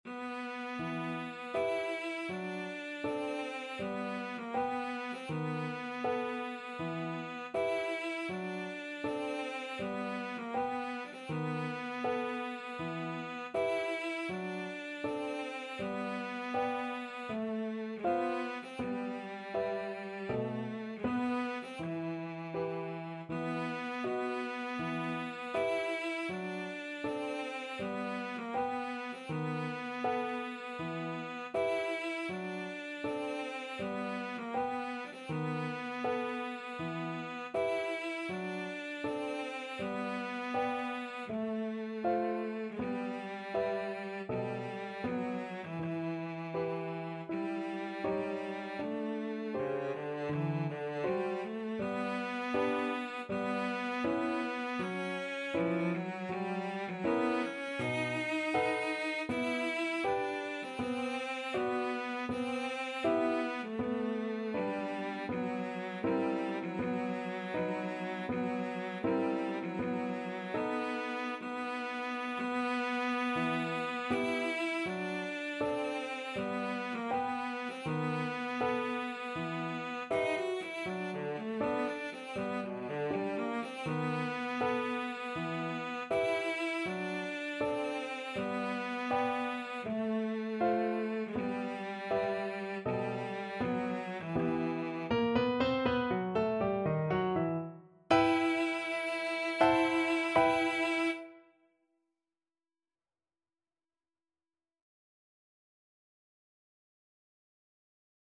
4/4 (View more 4/4 Music)
Andante = c.80
Classical (View more Classical Cello Music)